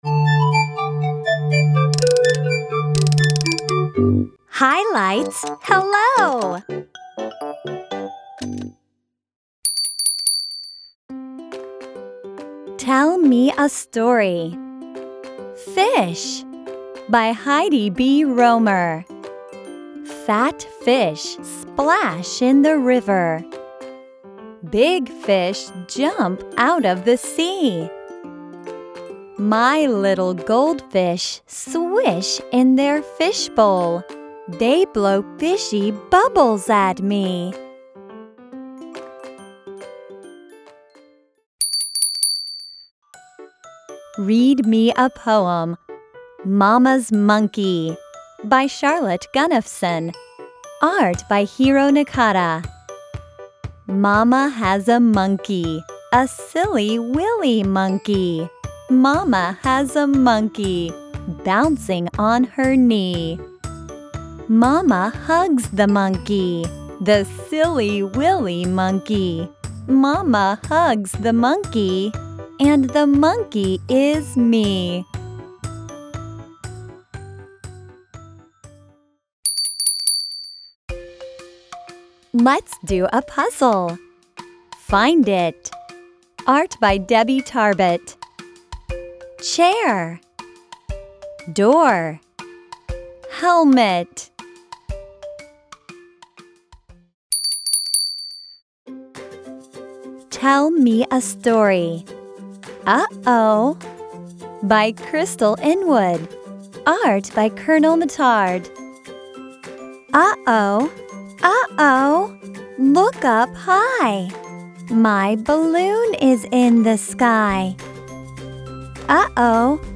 Below you will find audio narration of every book by a native English speaker.